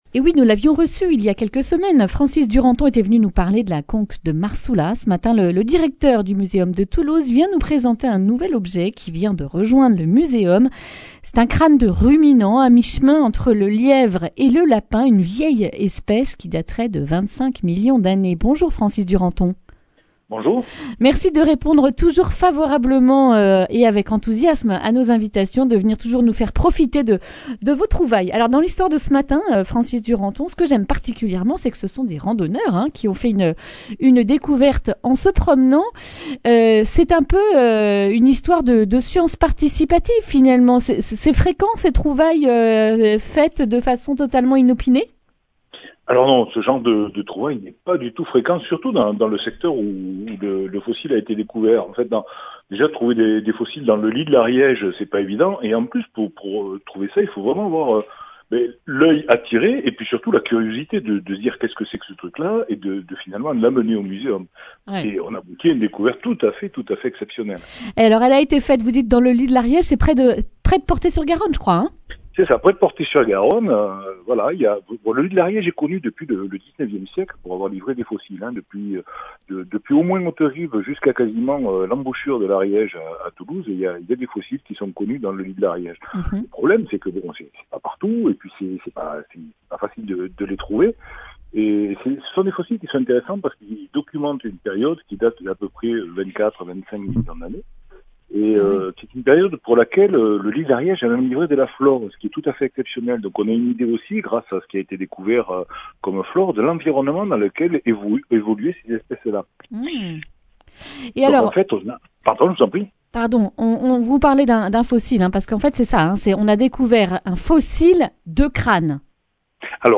mercredi 21 avril 2021 Le grand entretien Durée 10 min